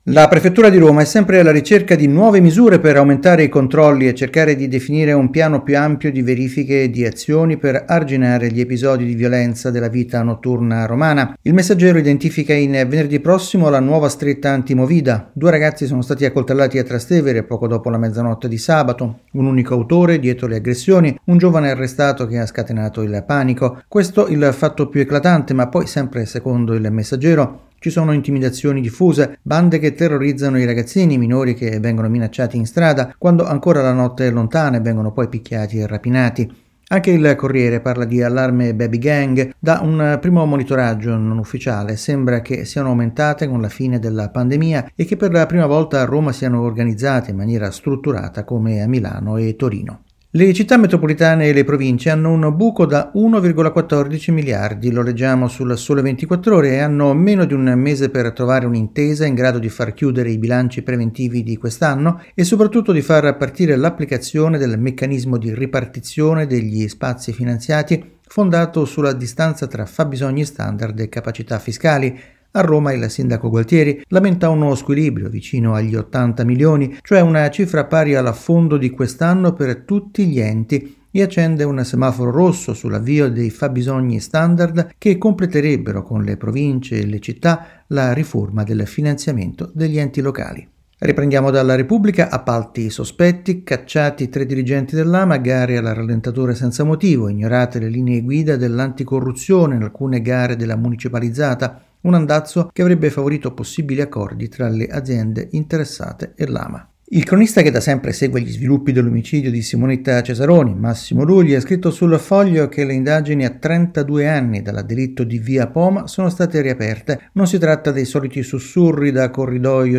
rassegna stampa